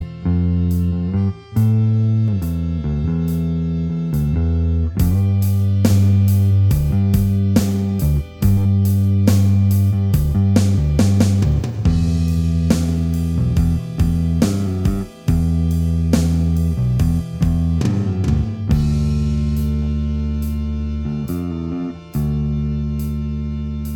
Minus Guitars Indie / Alternative 4:34 Buy £1.50